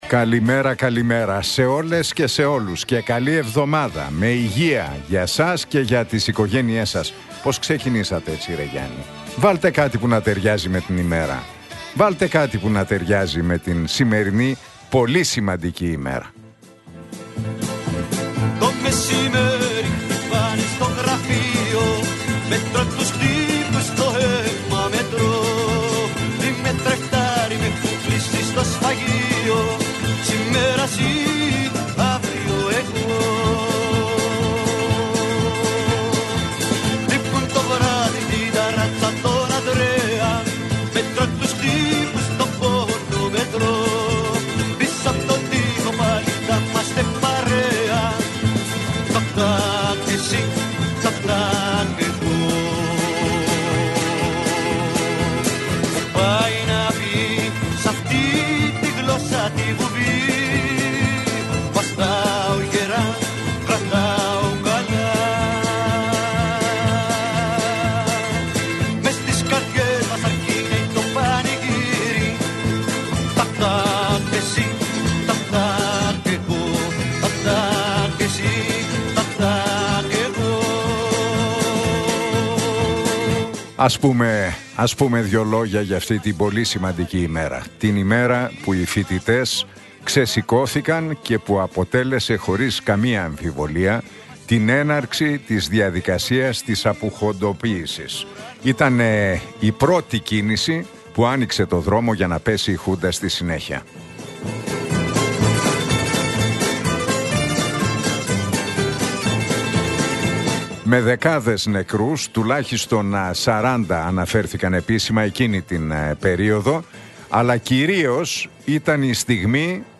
Ακούστε το σχόλιο του Νίκου Χατζηνικολάου στον ραδιοφωνικό σταθμό Realfm 97,8, την Δευτέρα 17 Νοεμβρίου 2025.